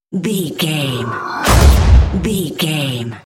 Airy whoosh explosion hit
Sound Effects
dark
intense
woosh to hit
the trailer effect